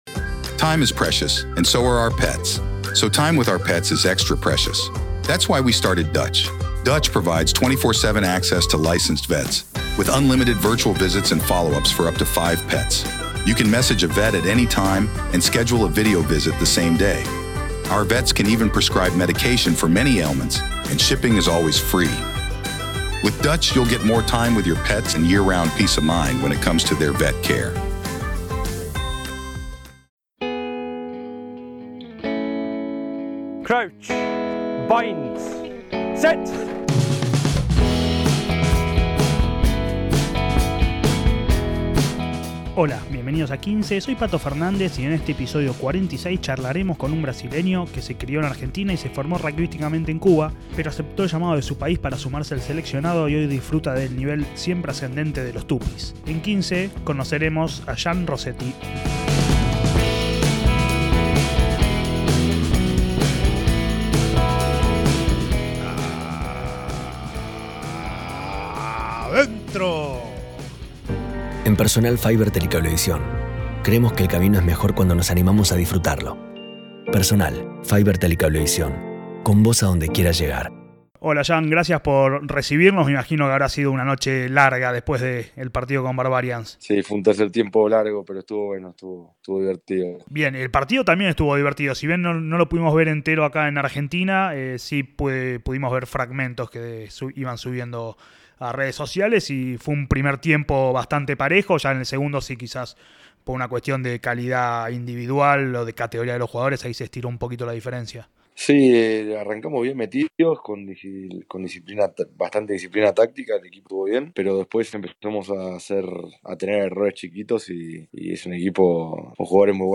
Charlas de rugby con los protagonistas!